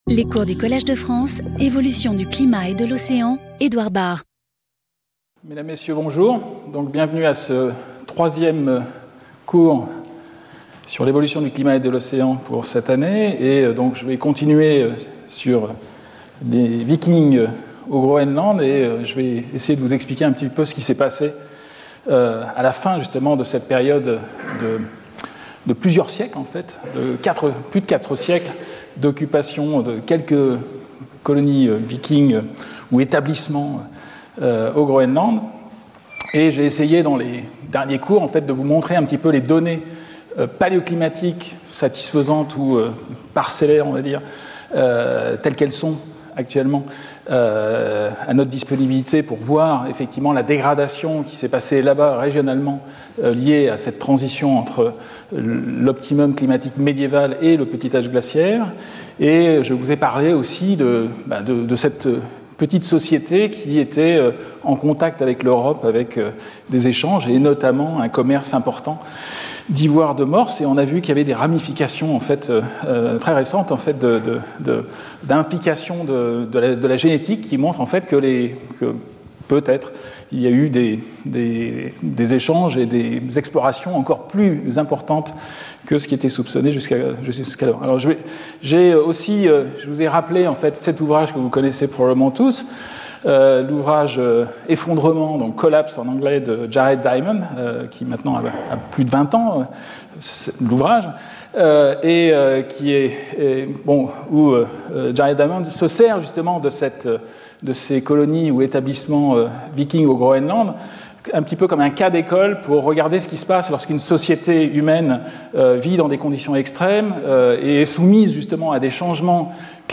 Cours